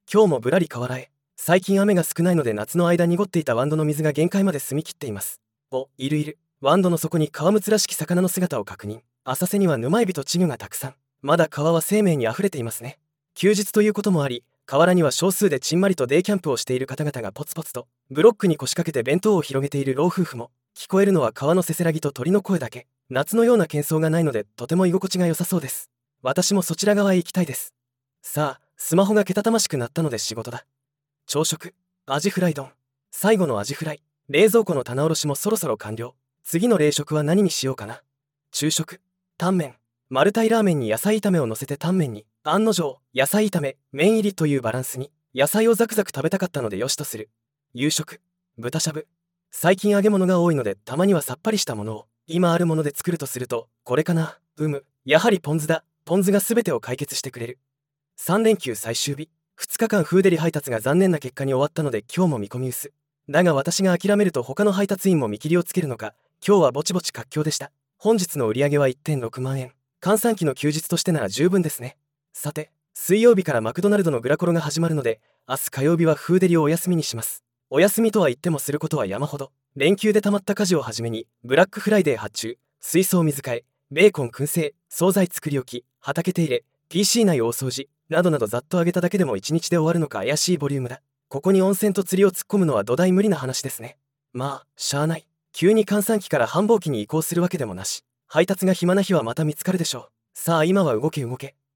今日もぶらり河原へ。
聞こえるのは川のせせらぎと鳥の声だけ。